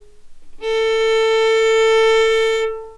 Obwohl die Tonhöhe bei allen Instrumenten bei 440 Hz liegt, sind noch klare klangliche Unterschiede wahrnehmbar
Geige_440Hz.wav